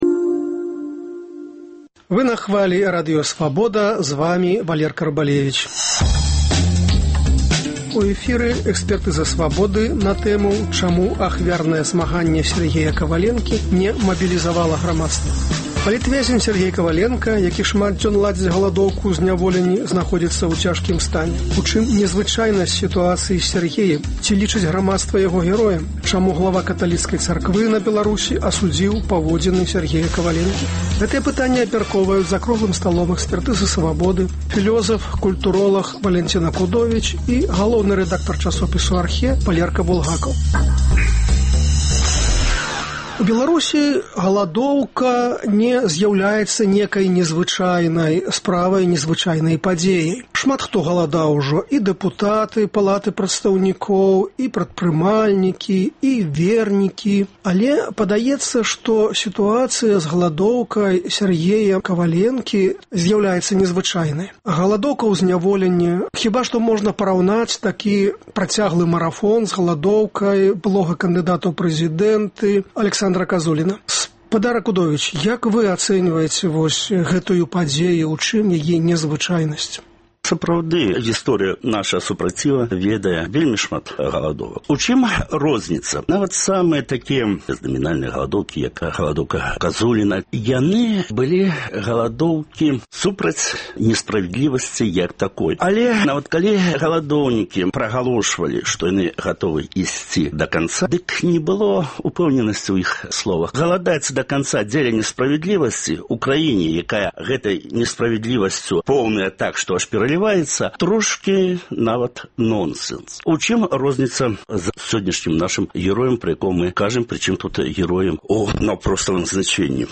Гэтыя пытаньні абмяркоўваюць філёзаф, культуроляг